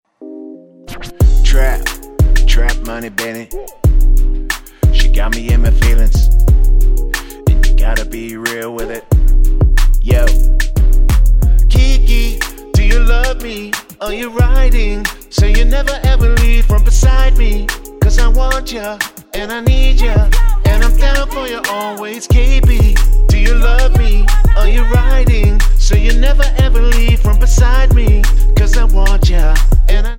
Tonart:Cm Multifile (kein Sofortdownload.
Die besten Playbacks Instrumentals und Karaoke Versionen .